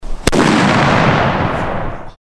Звуки петард